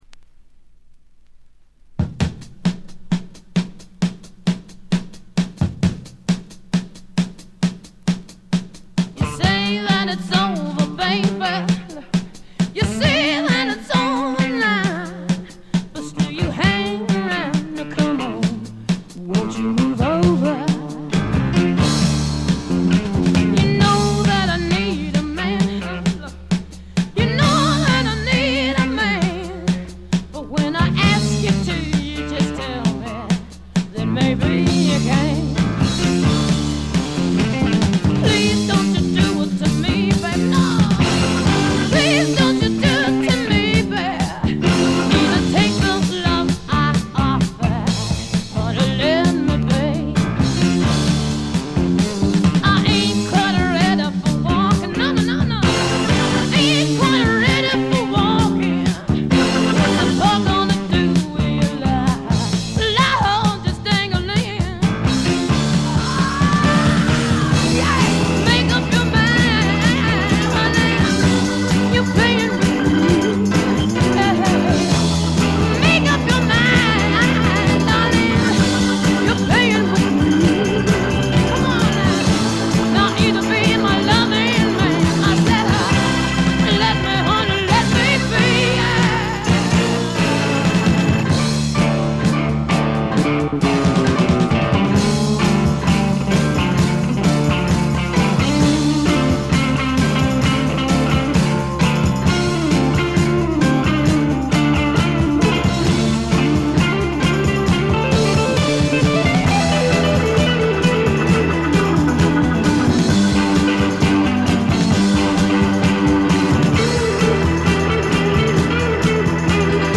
Vocals、Acoustic Guitar
Piano
Organ
Bass
Drums